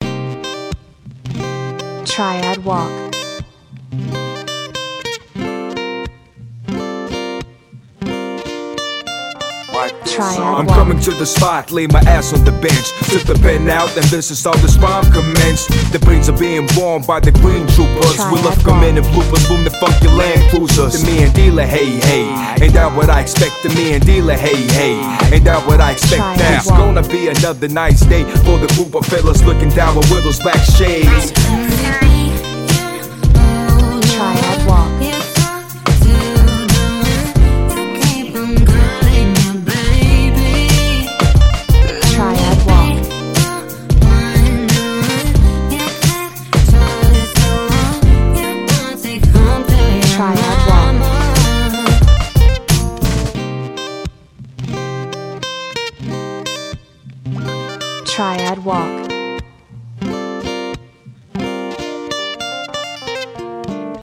女性ボーカル , 男性ボーカル , エレクトリックピアノ
アコースティックギター